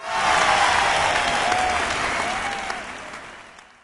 post_match_draw_cheer_02.ogg